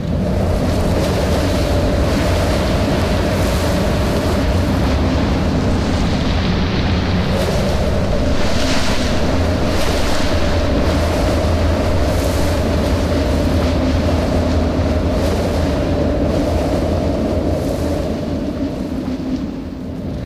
Wind2.ogg